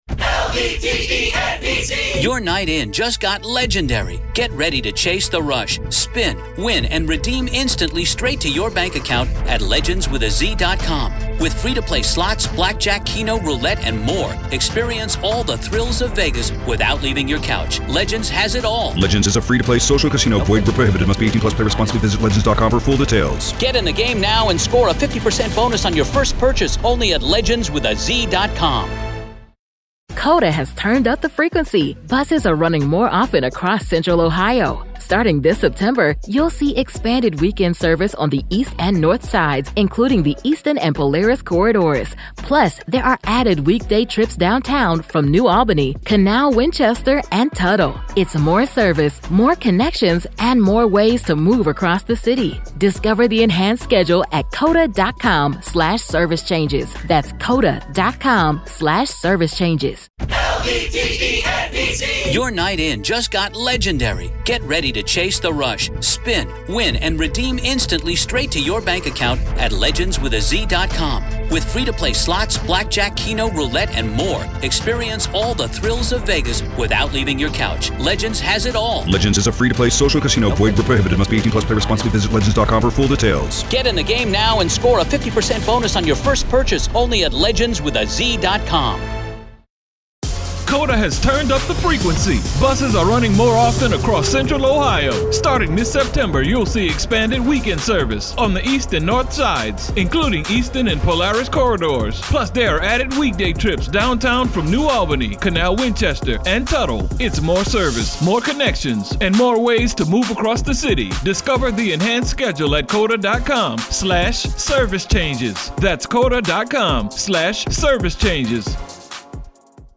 The hosts set the stage by pulling apart his narrative, highlighting the contradictions and pointing out how his story never quite holds up under scrutiny. This is the opening salvo of the prison writings — a look at how Daybell wants the world to see him, versus how his words reveal the truth.